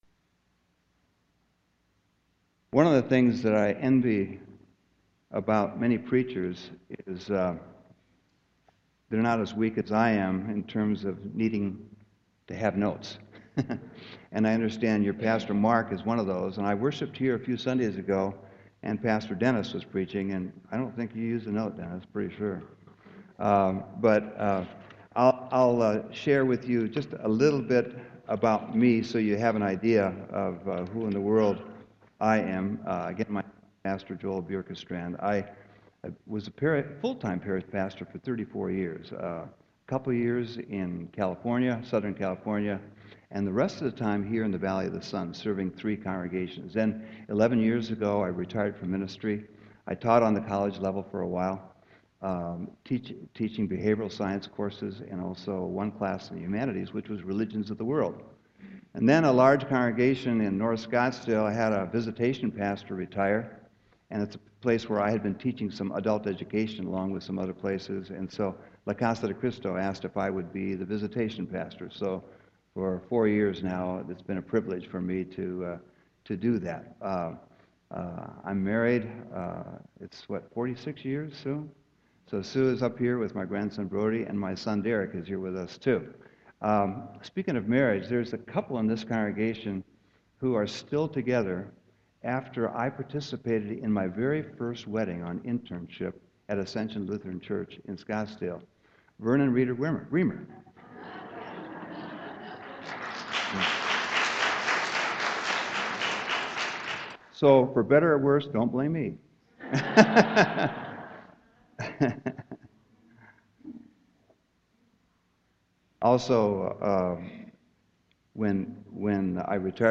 Sermon 7.5.2015